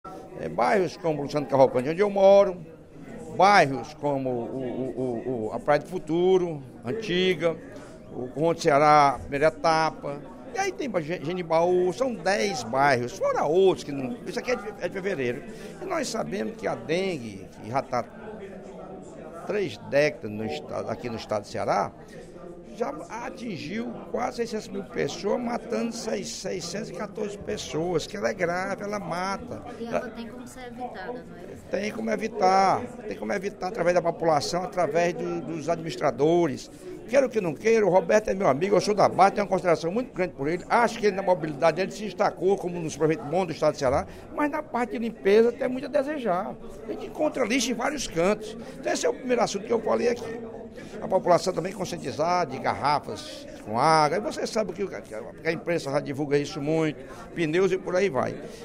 O deputado Lucílvio Girão (PP) demonstrou, no primeiro expediente da sessão plenária desta quinta-feira (23/03), preocupação com uma nova epidemia de dengue em Fortaleza.